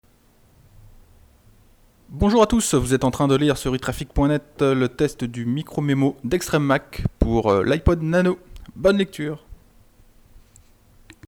Il s’agit de ne pas parler trop près de la bonnette sous risque d’entendre du bruit.
Un son valant mieux que toutes les phrases, voici un exemple rapide de ce que vous pourrez tirer du MicroMemo : SafariEcranSnapz001-173.jpg Les fichiers audio obtenus en qualité “Supérieure” sont au format Wav (facilement convertibles en MP3 avec iTunes), d’un débit de 1411 kbps et de 44,100 kHz d’échantillonnage.